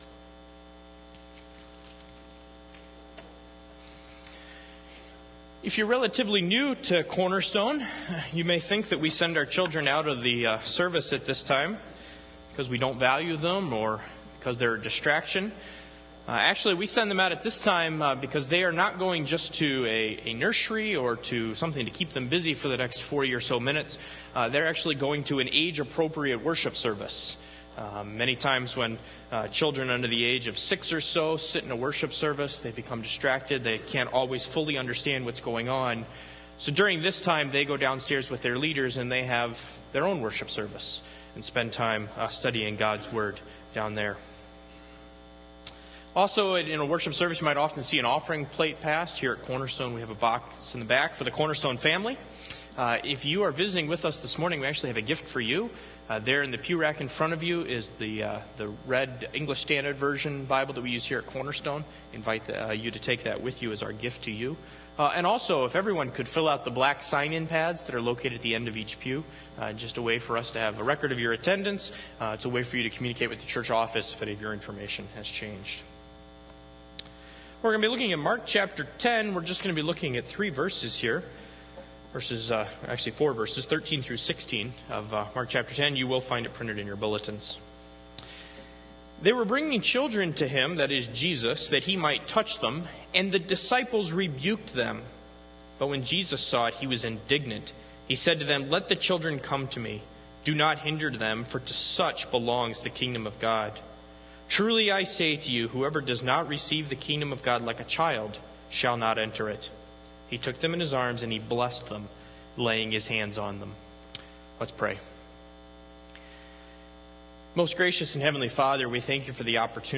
Cornerstone Presbyterian Church (PCA) - Sermons: Mark 10:13-16